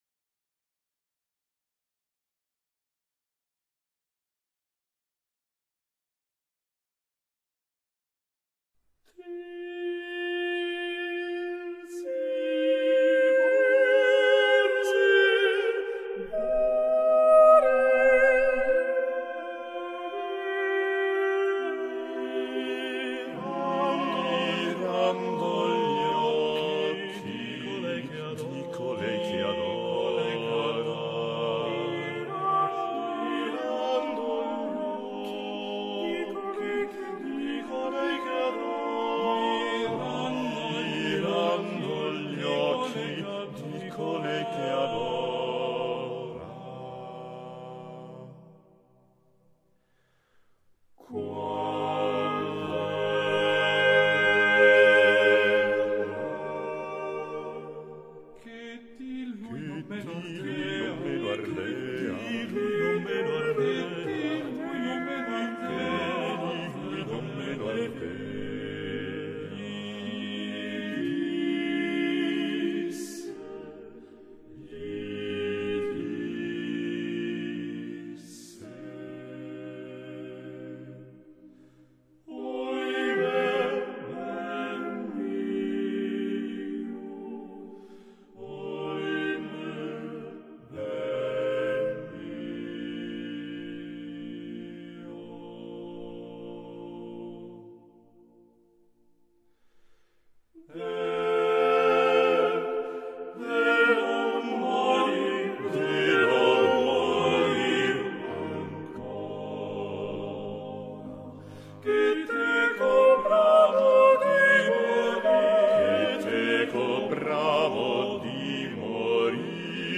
Tirsi morir volea | Guarini, Madrigale
Carlo Gesualdo, Libro I a 5 (1591) | Delitiae Musicae — partitura